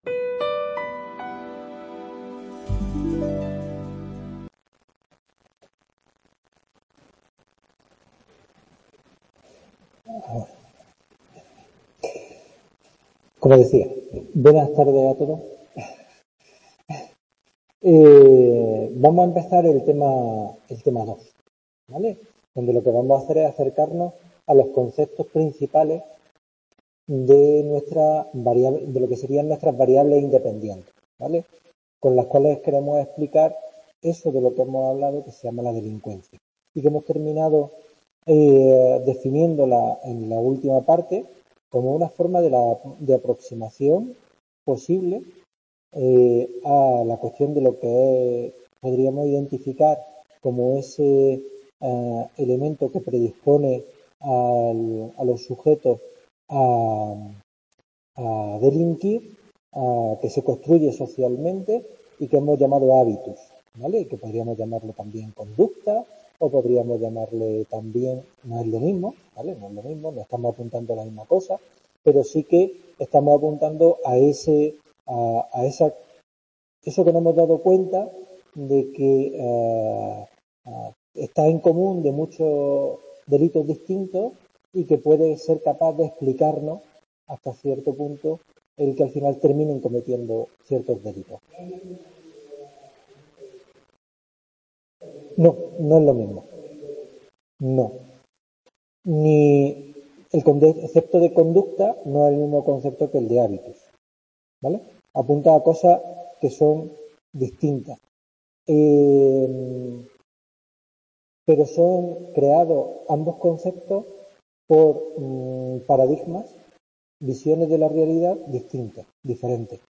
Esta videoclase trata sobre conceptos básicos como estructura social, campo social, experiencia social que utilizaremos para investigar la Vulnerabilidad Social.